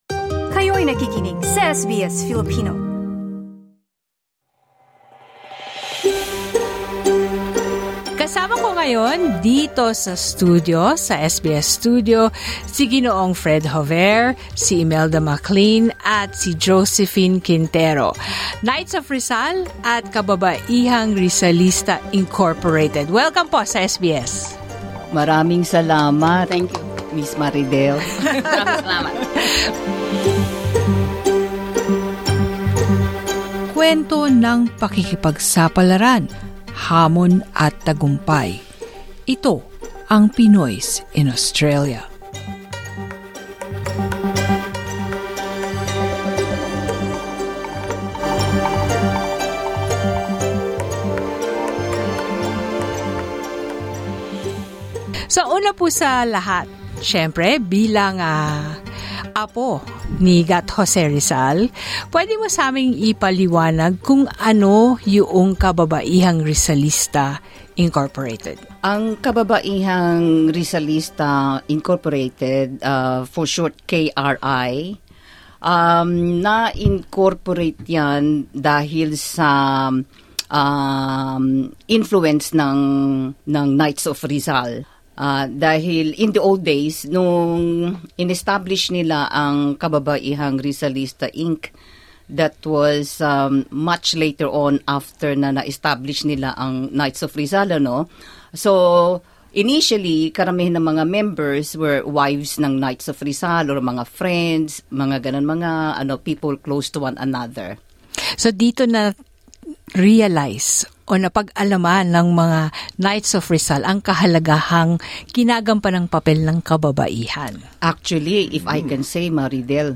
at the SBS Studios in Federation Square, Melbourne